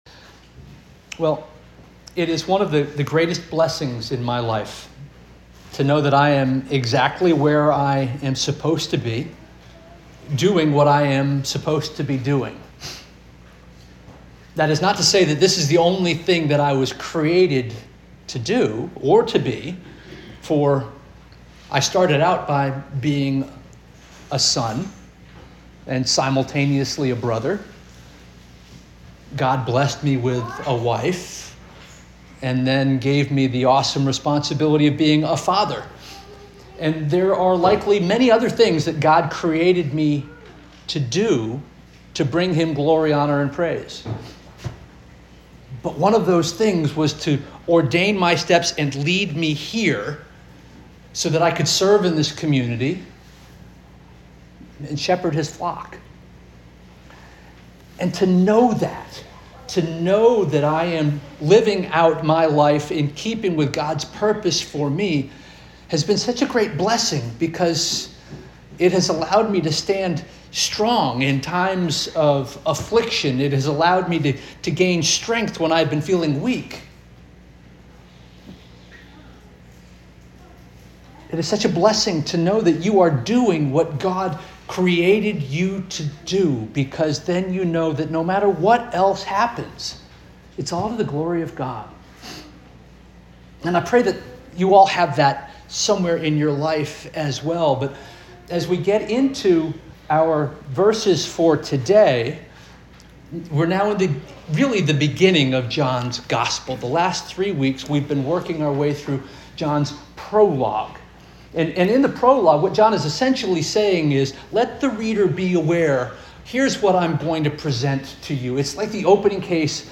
August 3 2025 Sermon